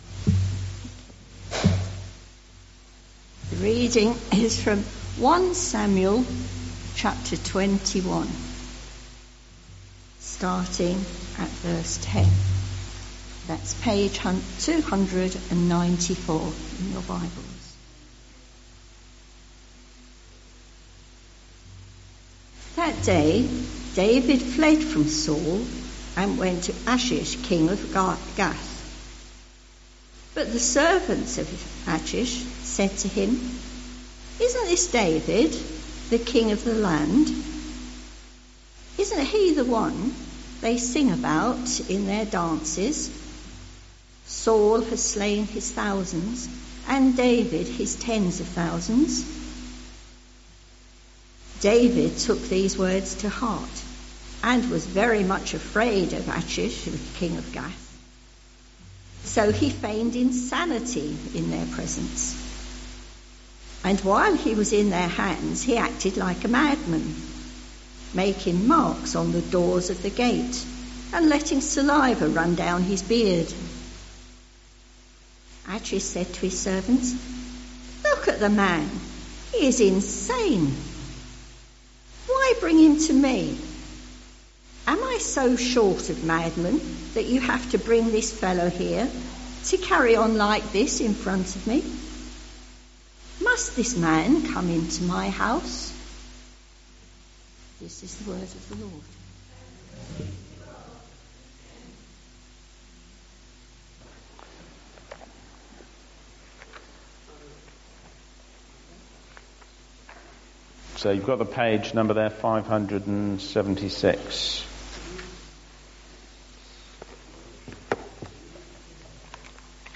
Dagenham Parish Church Morning Service
Lamentations 1 Service Type: Sunday Morning